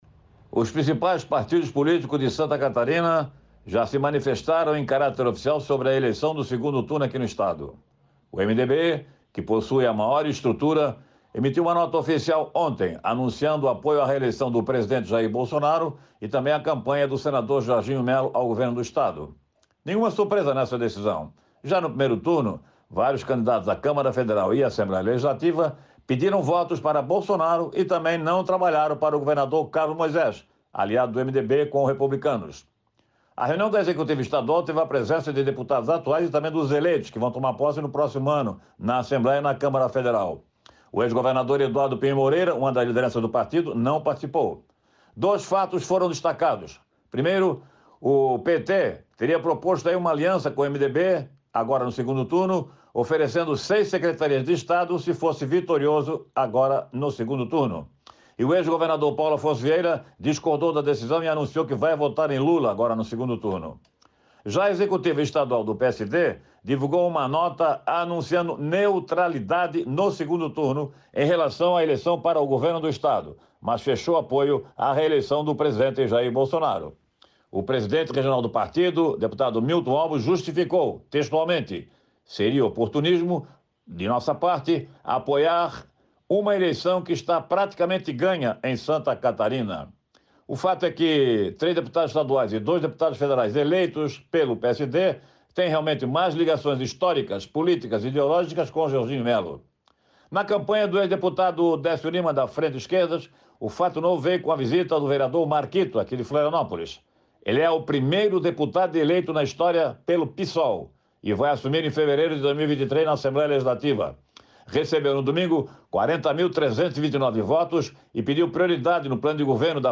Jornalista destaca que, em Santa Catarina, os principais partidos políticos já se manifestaram em caráter oficial sobre apoio no 2º turno das eleições